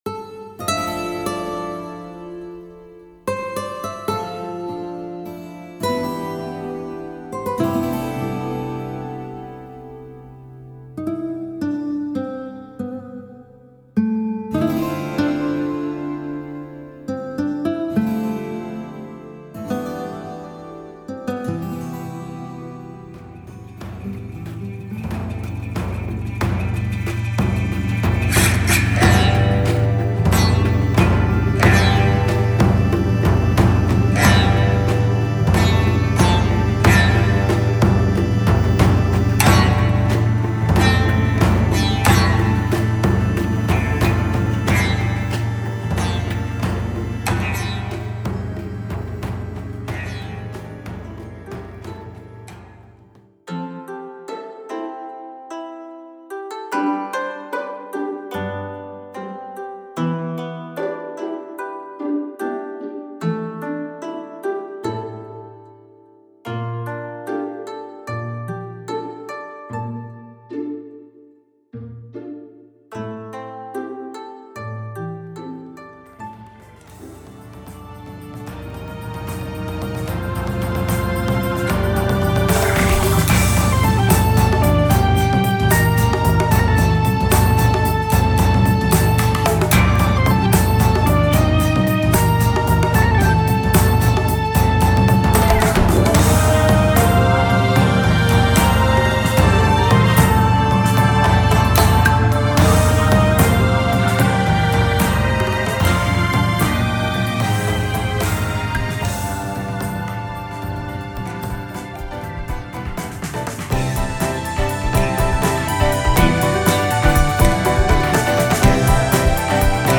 舞台音楽集
能や雅楽、プログレッシヴロックをベースに、緩急織り交ぜ、情感豊かに組み上げた、
ダイジェストサンプル